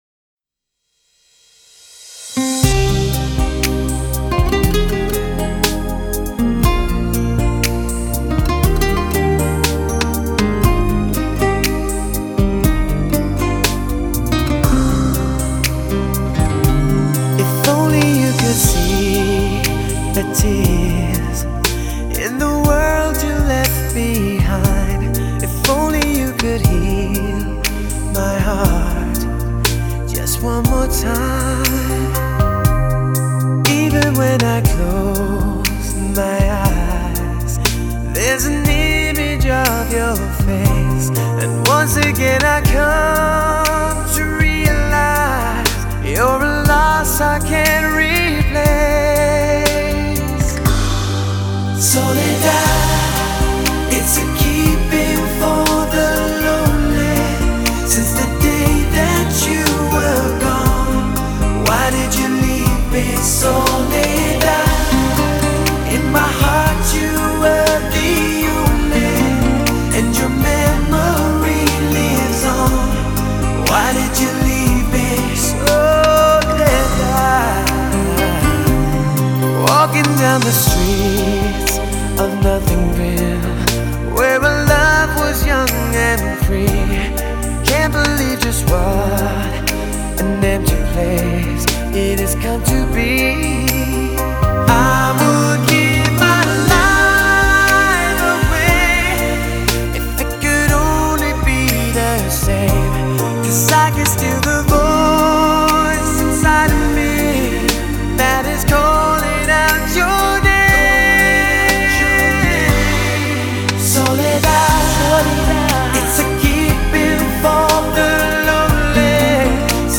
медленные песни